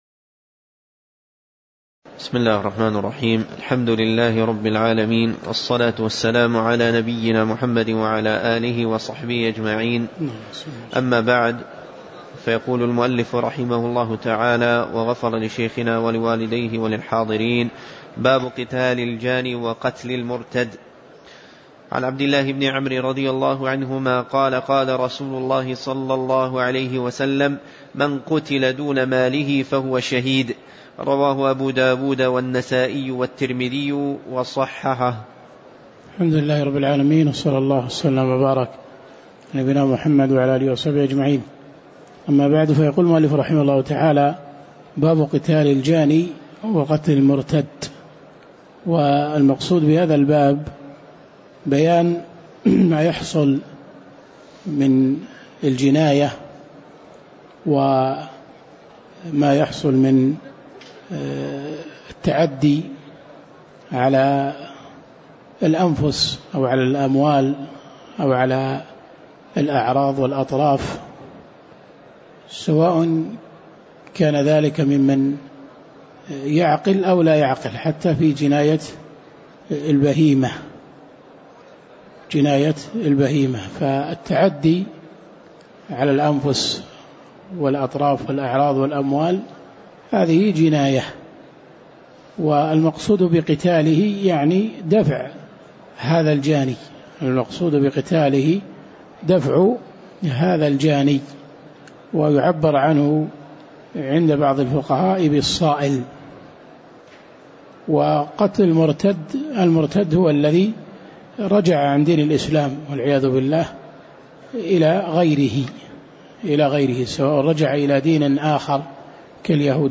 تاريخ النشر ١ شعبان ١٤٣٩ هـ المكان: المسجد النبوي الشيخ